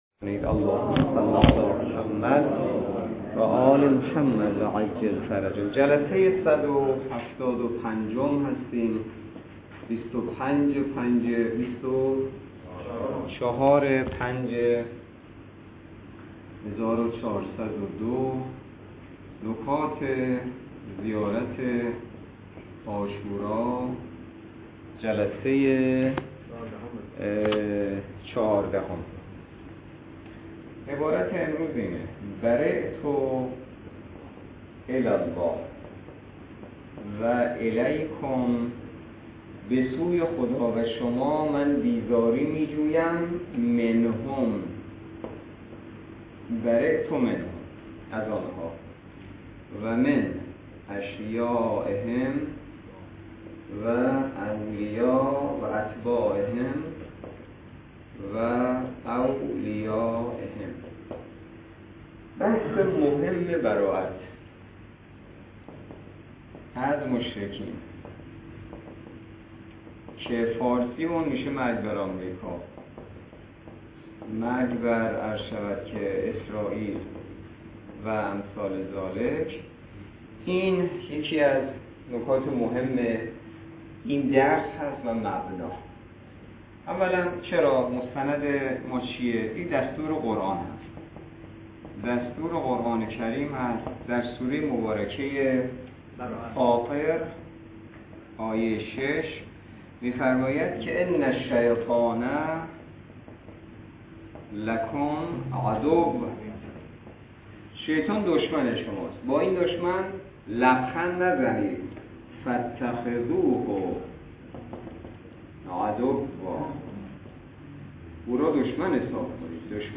درس فقه الاجاره نماینده مقام معظم رهبری در منطقه و امام جمعه کاشان - جلسه صد و هفتاد و پنج